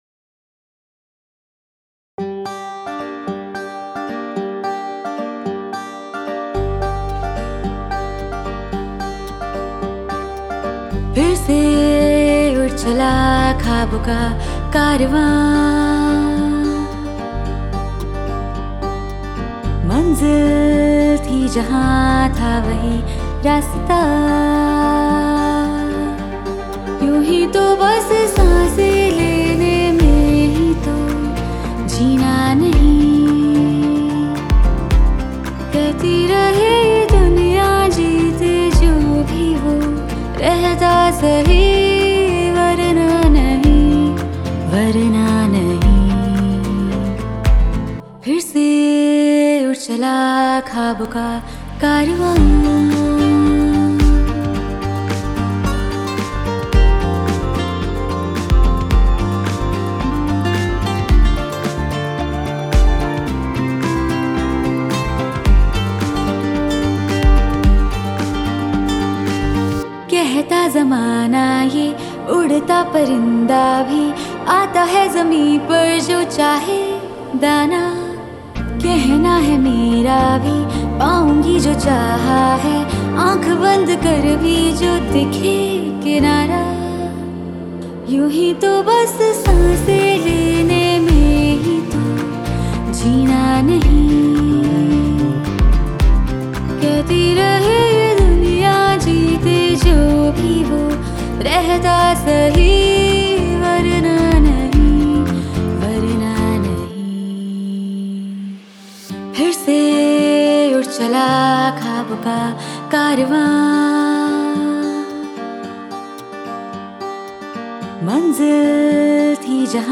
INDIPOP MP3 Songs